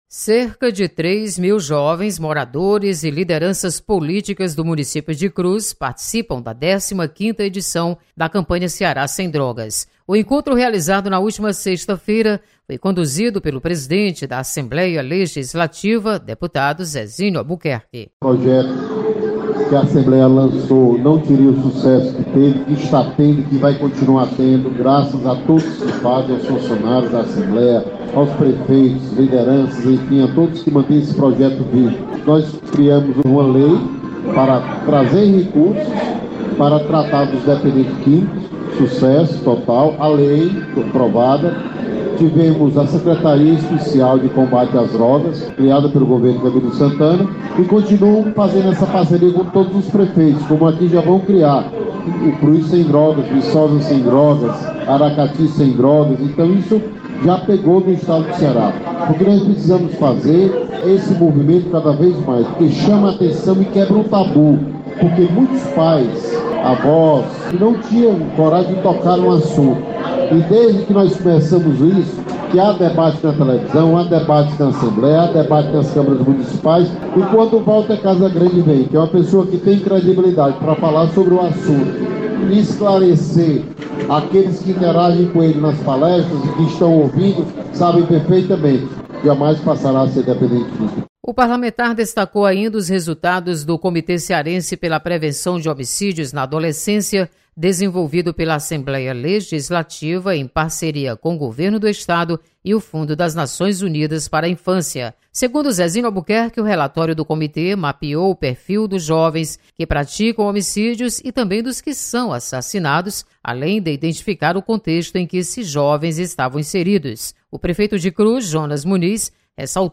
Campanha Ceará Sem Drogas mobiliza milhares de jovens na cidade de Cruz. Repórter